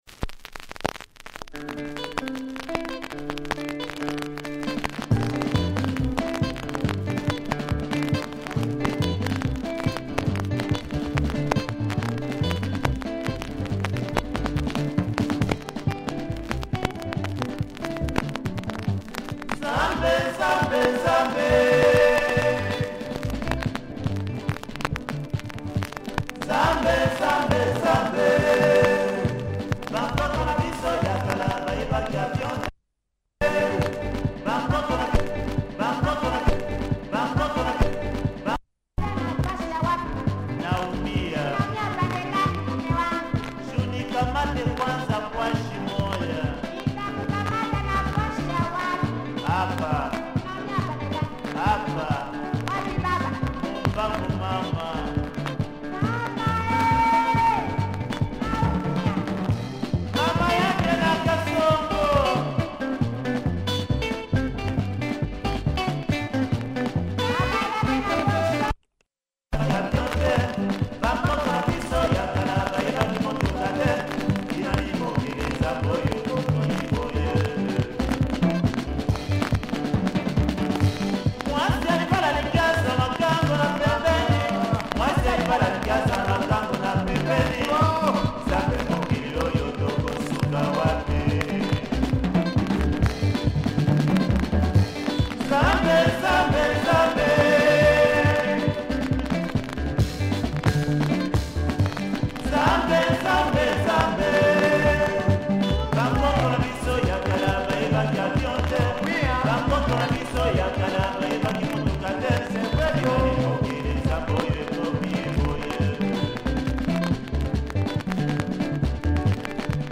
Ridden copy but plays, check audio below. https